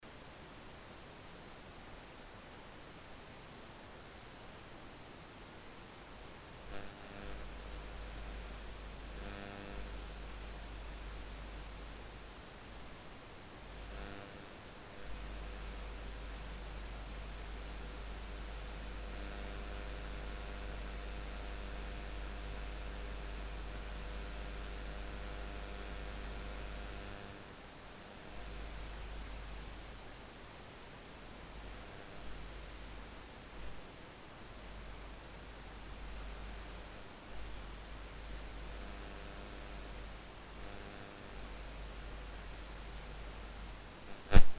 Но когда стали играть акустику, то я наметил негромкий, но очень неприятный звук на заднем плане.
Проявляется спонтанно, в зависимости от ракурса и наклона приёмника (точной закономерности не обнаружил).
Прилагаемый аудио-пример я естественно увеличил по громкости, но поверьте - его слышно отчётливо и при рабочих настройках громкости, в паузах между музыкой или в тихих её местах.